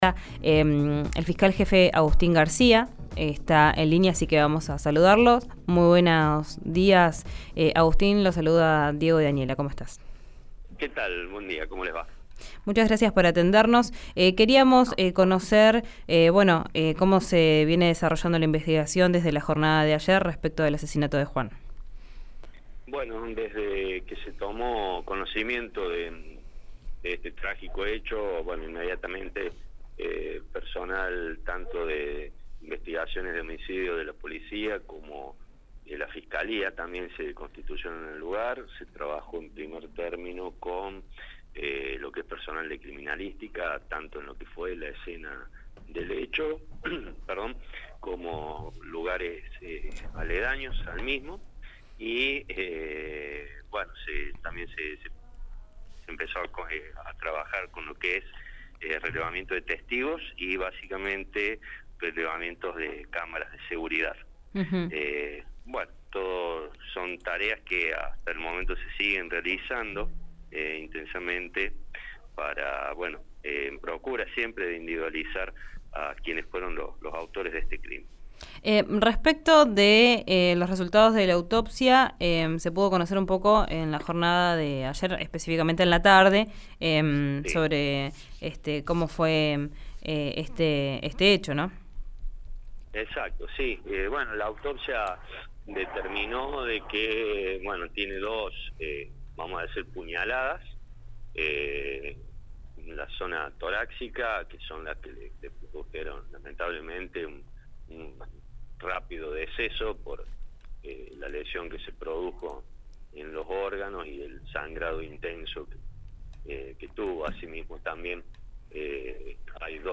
Escuchá a Agustín García, fiscal jefe de Neuquén, en RÍO NEGRO RADIO: